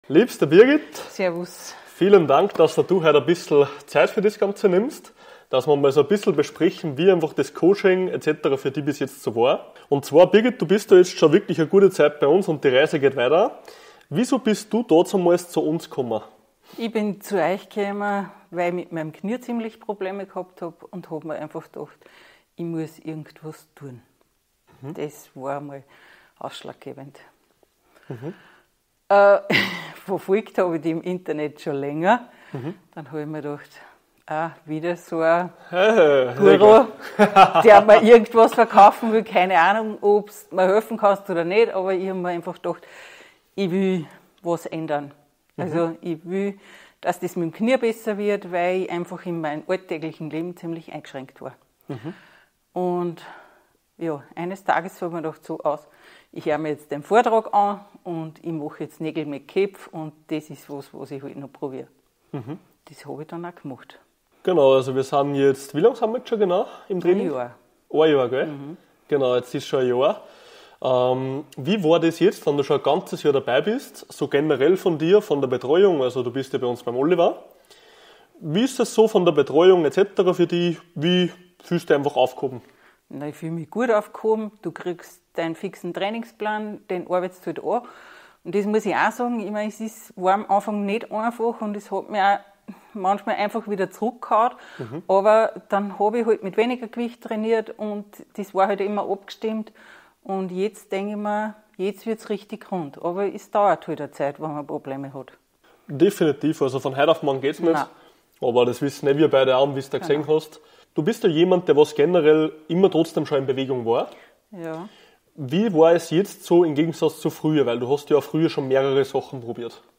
Kundeninterview